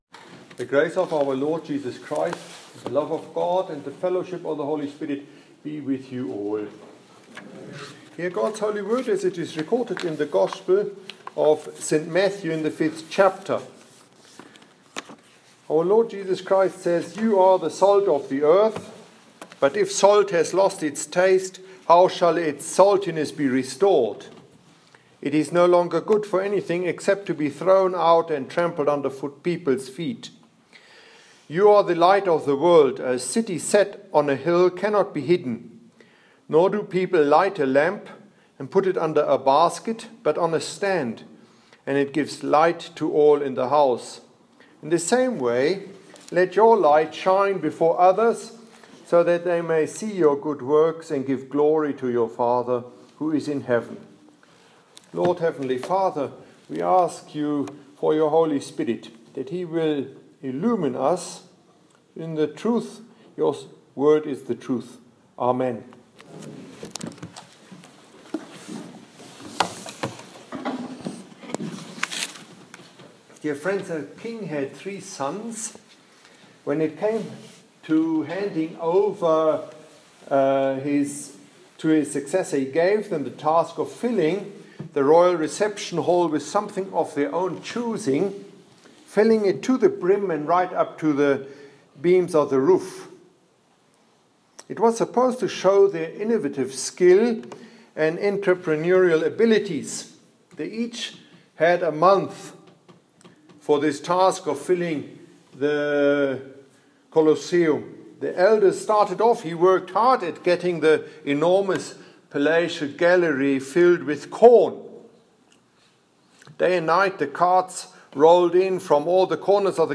Here is this mornings sermonette on yesterday's gospel St.Matthew 5:13-16 read during Matins.